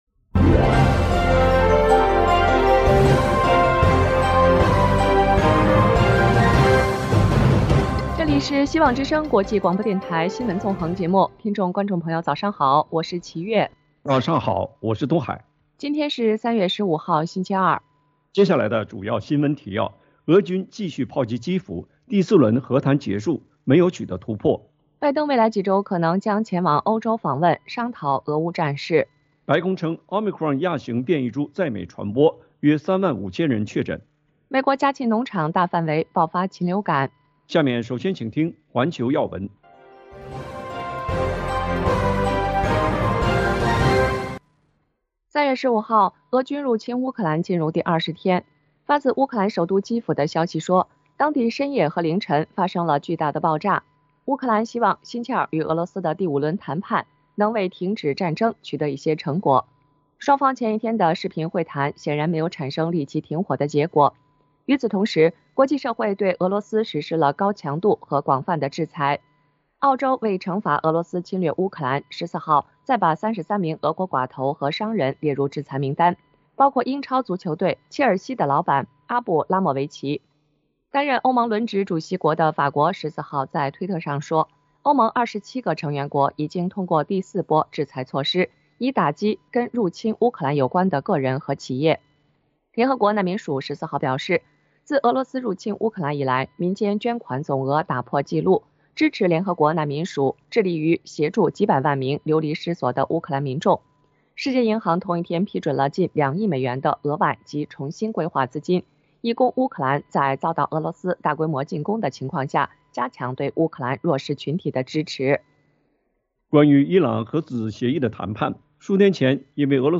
美中高层激烈对谈7小时 关切台海情势中俄结盟【晨间新闻】